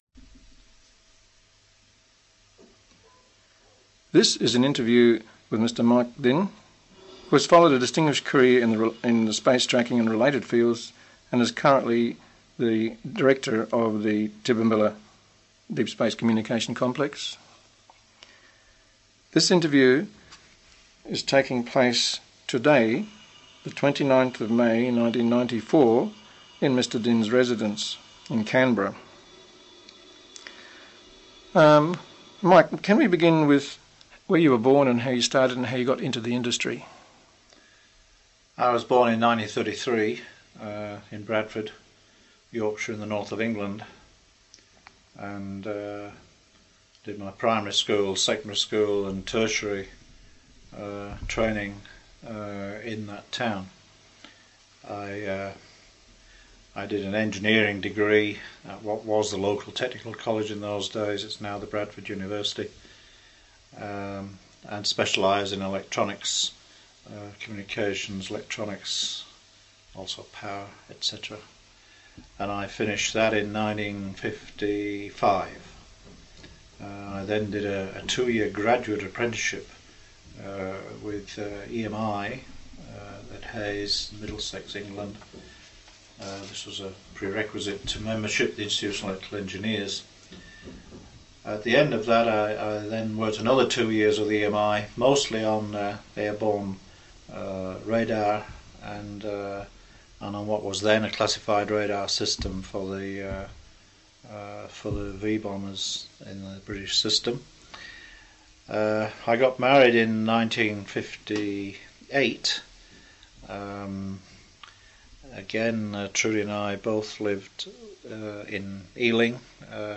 interview 1994